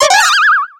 Cri de Mime Jr. dans Pokémon X et Y.